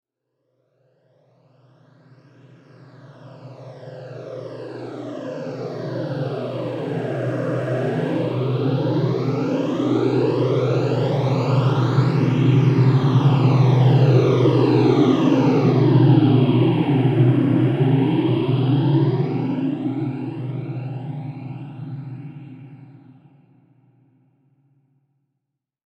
Creepy-space-transition-sound-effect-sci-fi-horror-atmosphere.mp3